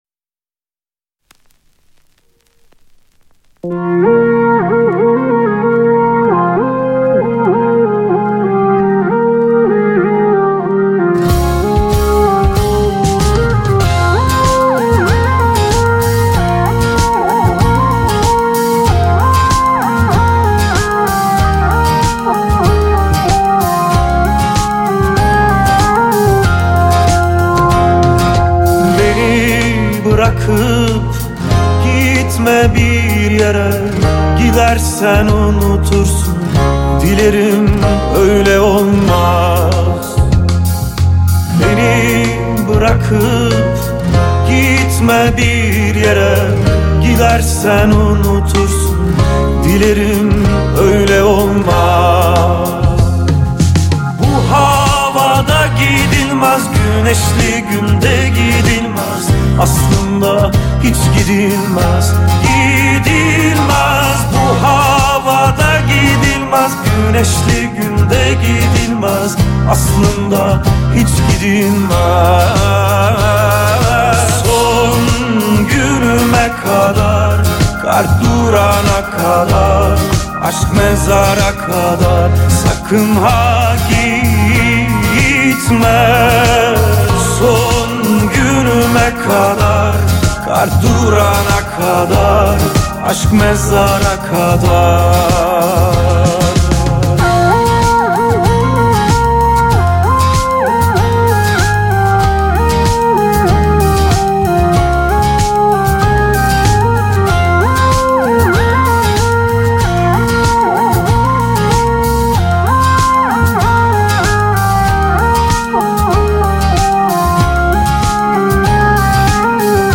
آهنگ ترکی ترکیه ای جدید